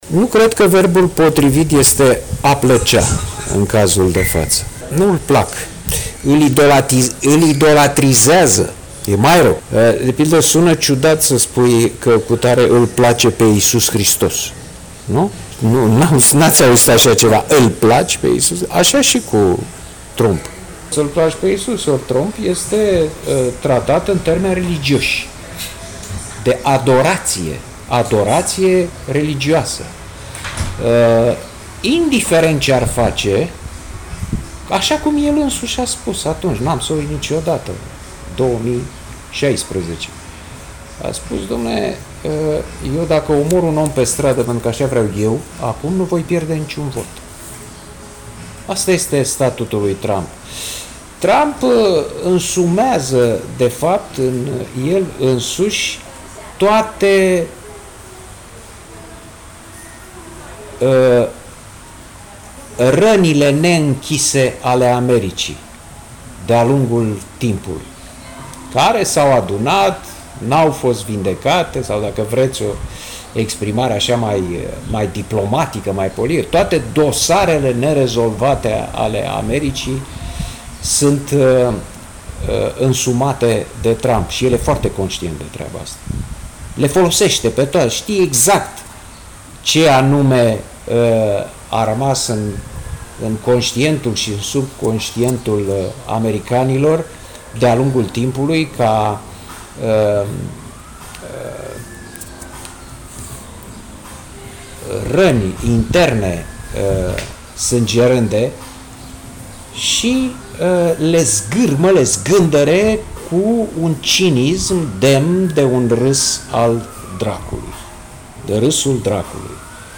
Publicistul Cristian Tudor Popescu a acordat un interviu Radio HIT pe un subiect fierbinte în SUA după ultima prima dezbatere dintre Trump și Biden. De ce îl plac americanii pe Trump?